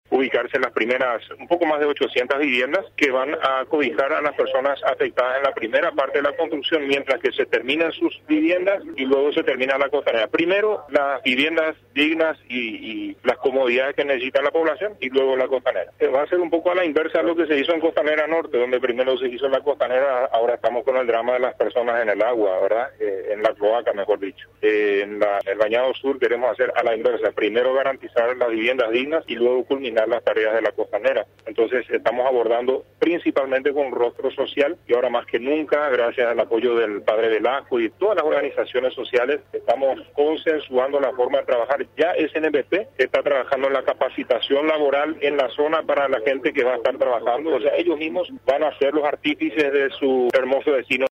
El ministro de Obras Publicas y Comunicaciones (MOPC) Arnoldo Wiens, explicó que se dará prioridad a la construcción de viviendas dignas.